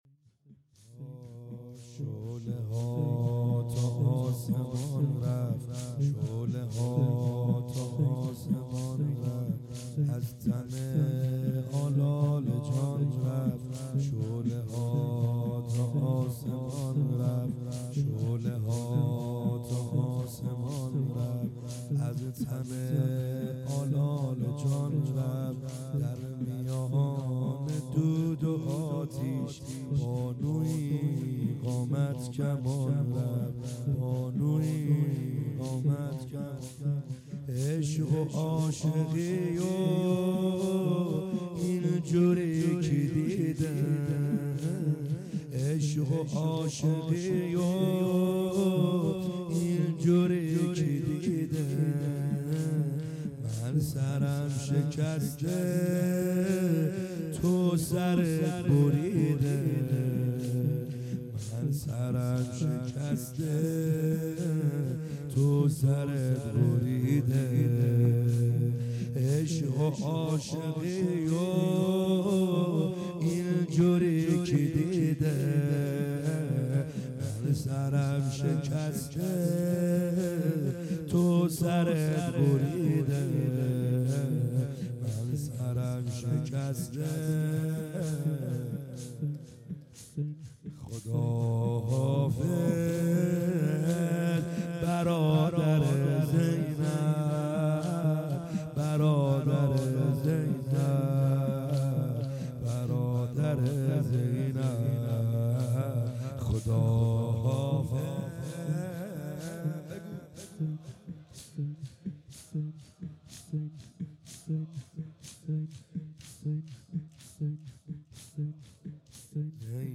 خیمه گاه - بیرق معظم محبین حضرت صاحب الزمان(عج) - زمینه | شعله ها تا آسمون